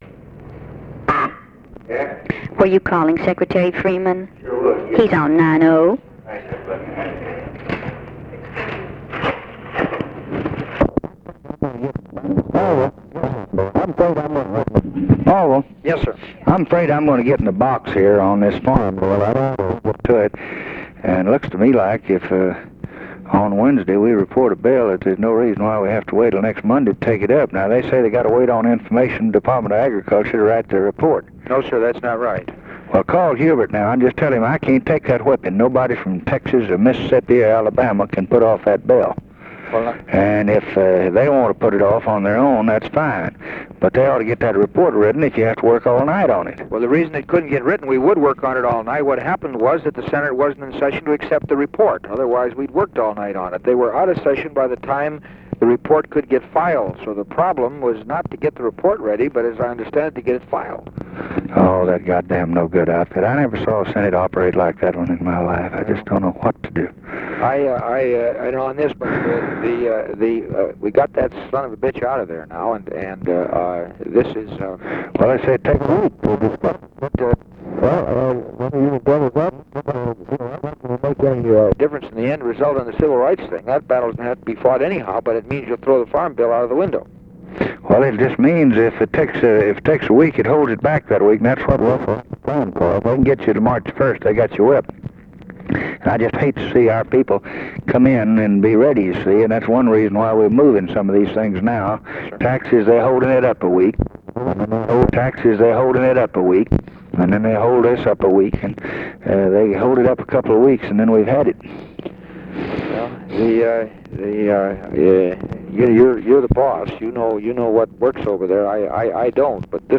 Conversation with ORVILLE FREEMAN, February 19, 1964
Secret White House Tapes